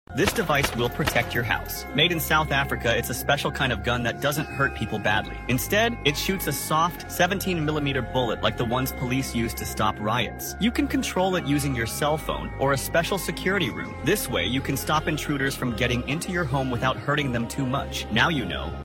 New High Tech Security Gun Sound Effects Free Download